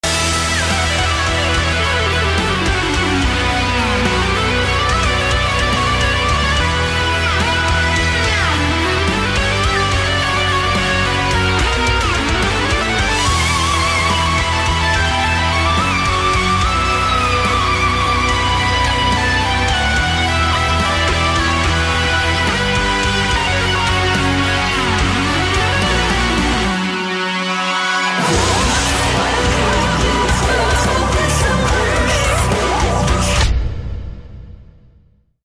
keyboard virtuoso
live drums